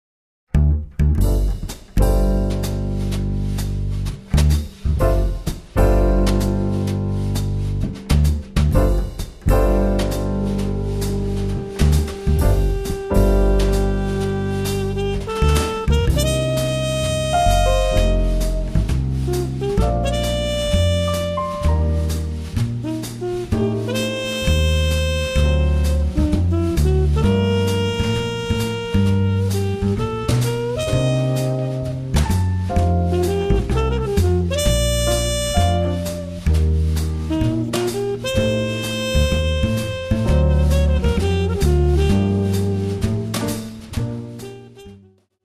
3p to 6p Jazz Band Bass, Piano, Drums, Vocals, Horns, Guitar